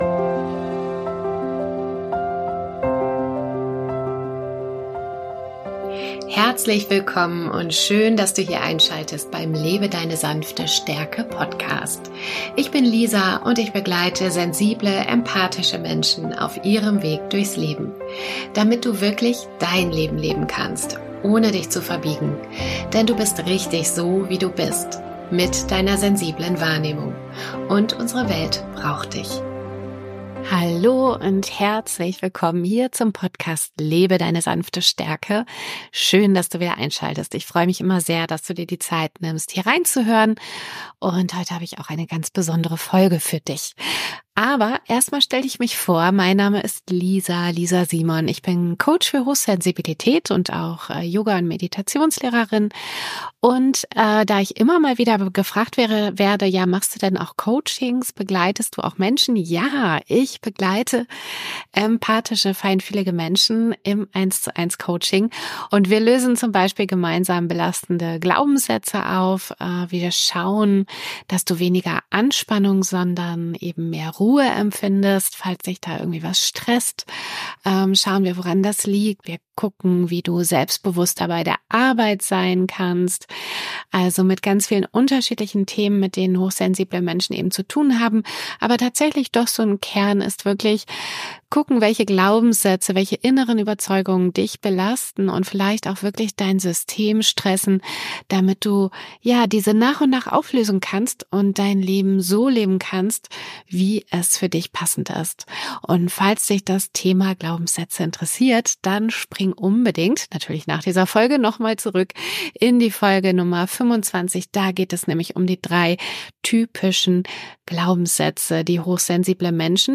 Wir reflektieren über das Loslassen von alten Mustern und die bewusste Gestaltung der verbleibenden Wochen des Jahres. Zum Abschluss erwartet Dich eine geführte Visualisierung, die Dir hilft, Sorgen und belastende Gedanken loszulassen und mit Freude den Herbst zu genießen.